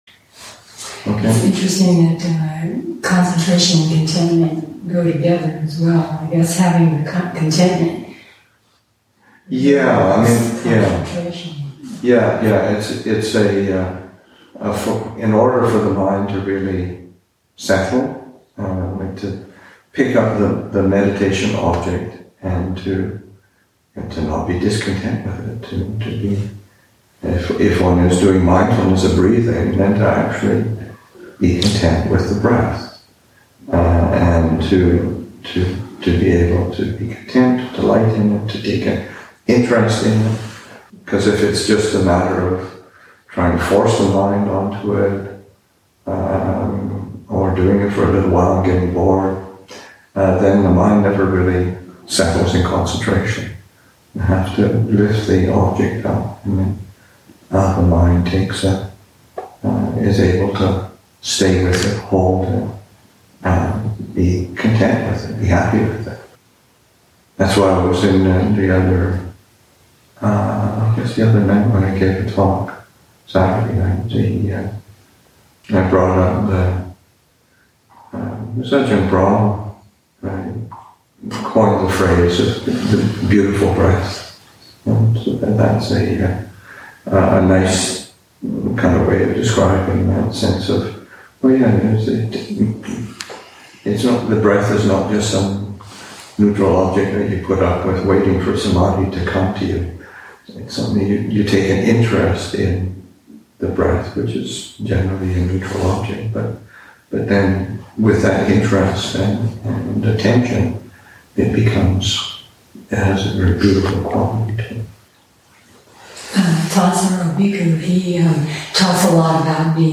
2. Discussion of contentment as necessary for concentration and interest in the breath.